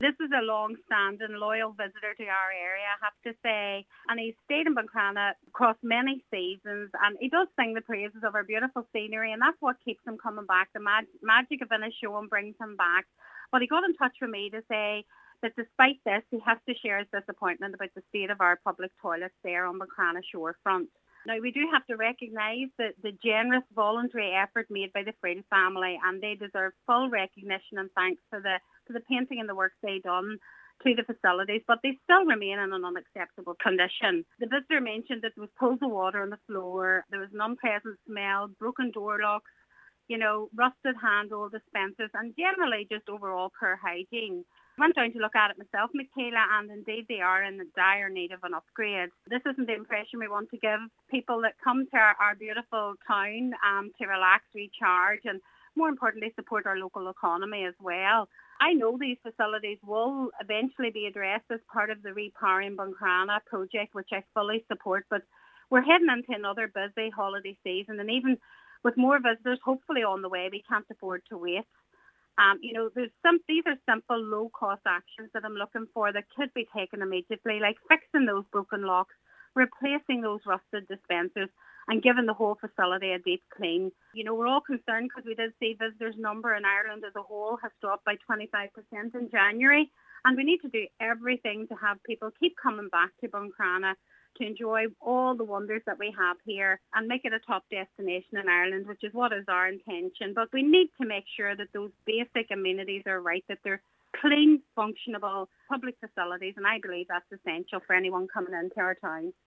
She says it’s important that works are carried out to retain visitors in the town: